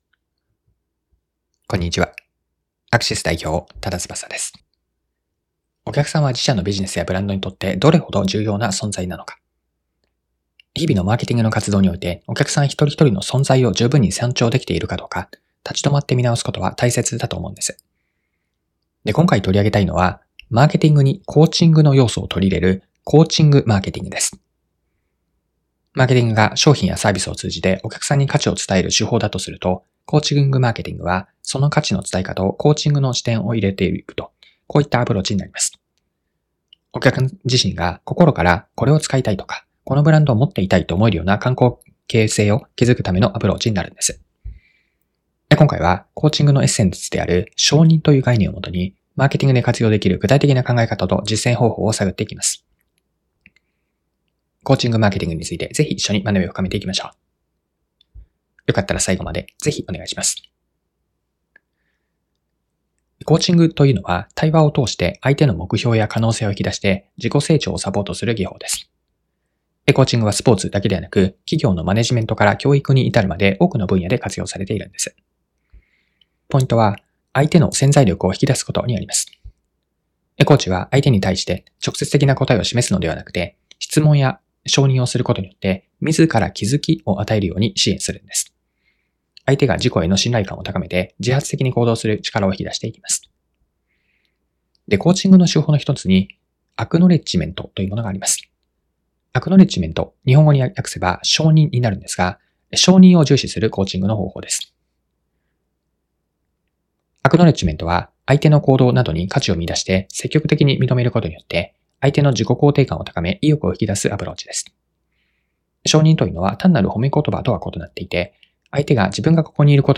Audio Channels: 1 (mono)